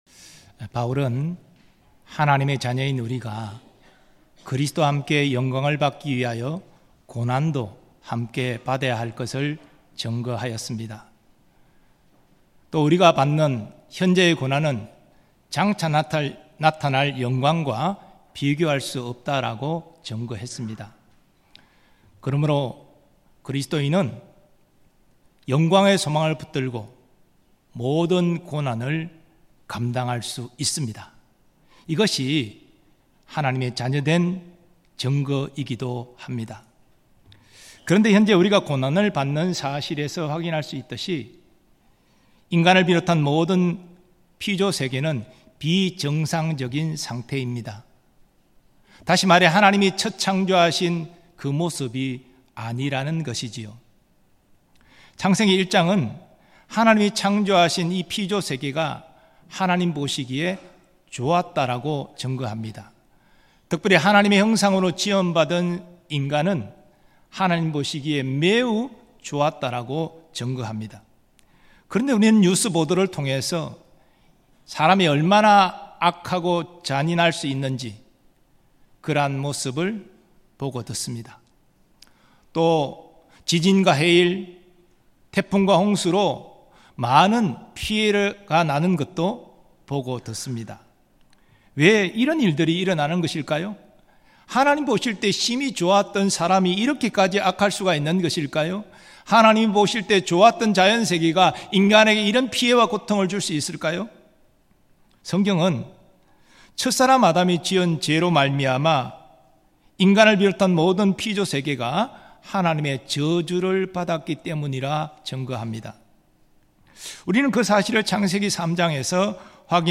2024년 8월 11일 주일예배말씀
신자가 피조물들과 더불어 가진 소망 음성설교 듣기 MP3 다운로드 목록 이전 다음